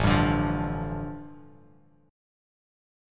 A7sus4#5 chord